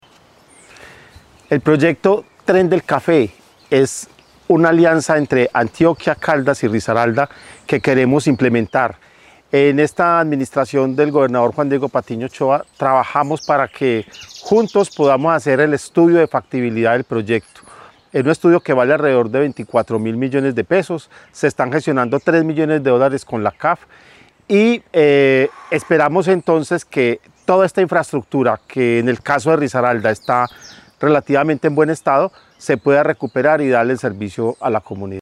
Jorge Hernando Cote, secretario de Infraestructura de Risaralda